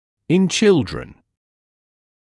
[ɪn ‘ʧɪldrən][ин ‘чилдрэн]у детей